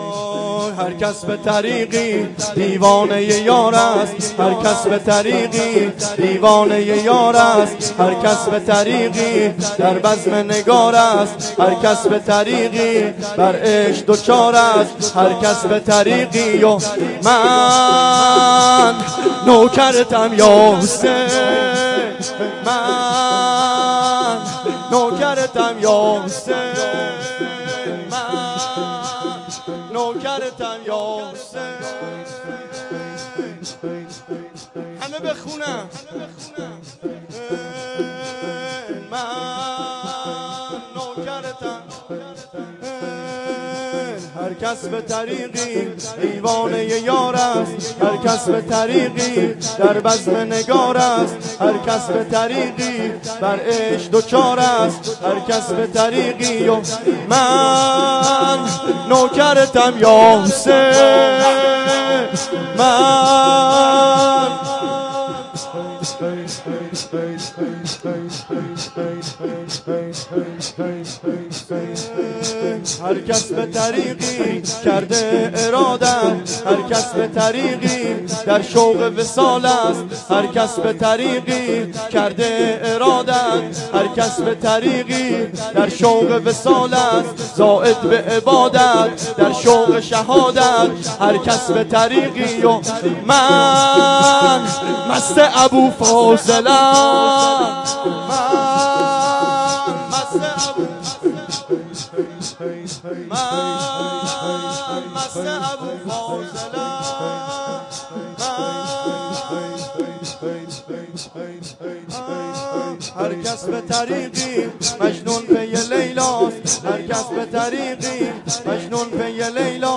شور
شب دوم ماه محرم